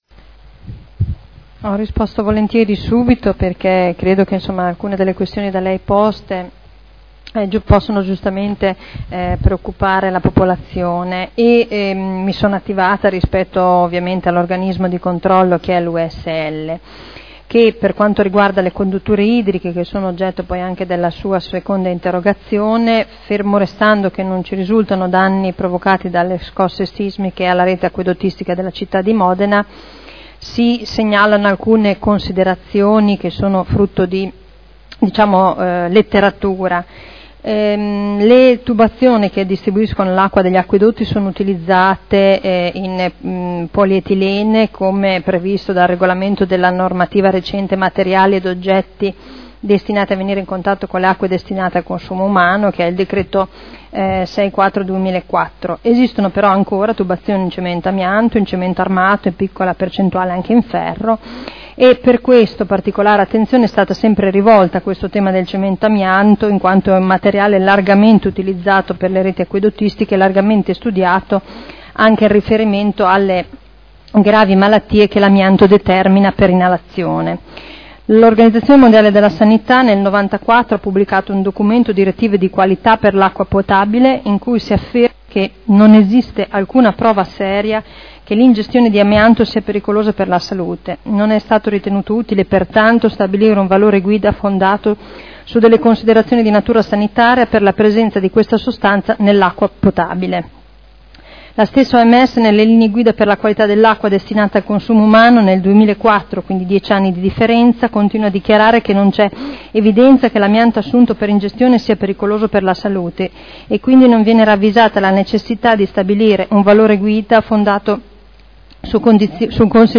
Simona Arletti — Sito Audio Consiglio Comunale